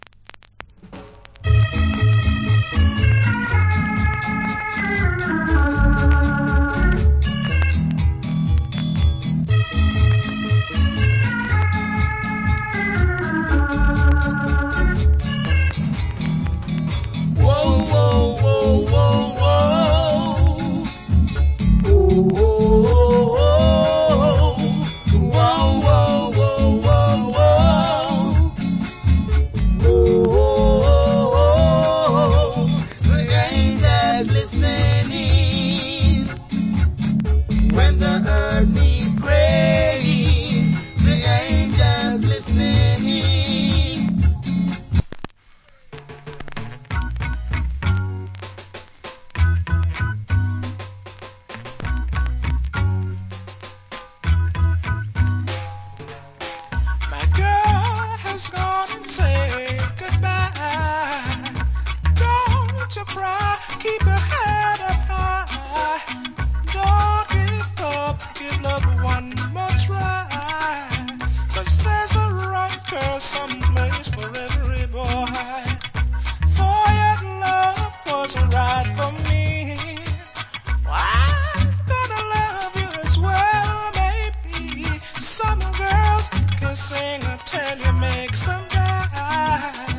Early Reggae.